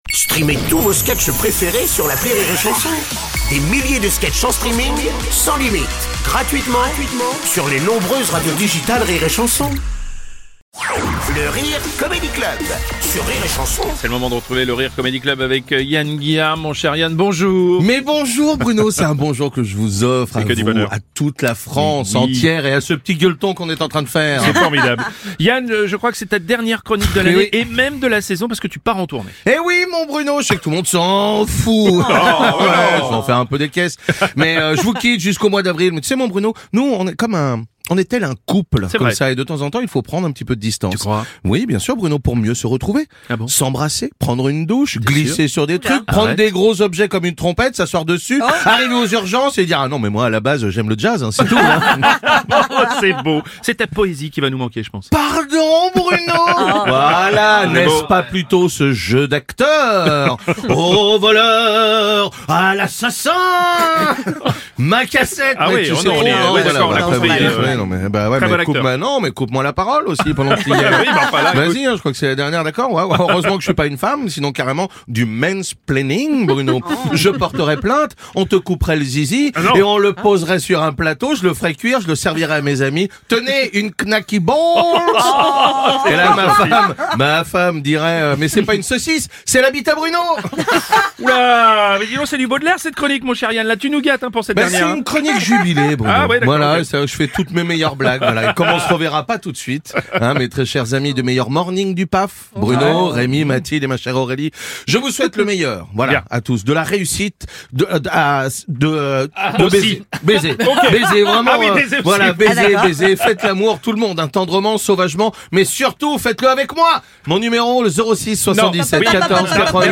L’humoriste Yann Guillarme décortique l’actu... à sa manière !